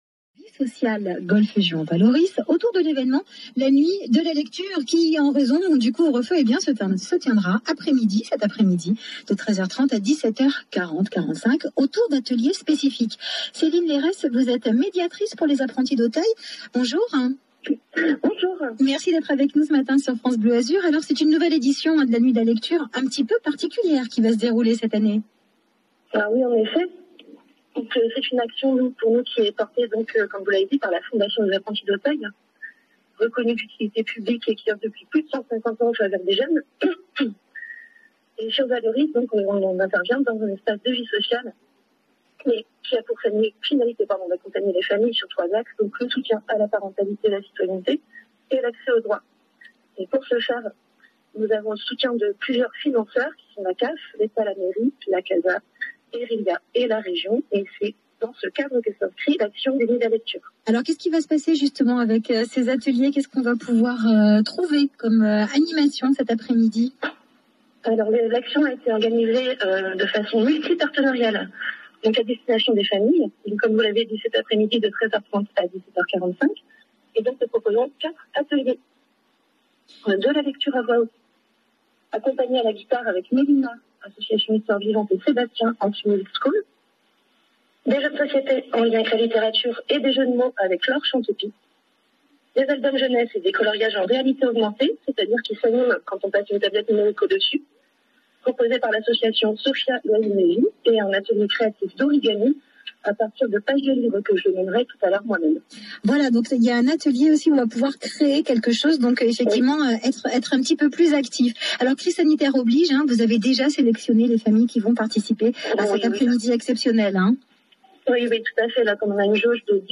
La suite de l’interview par France Bleu Azur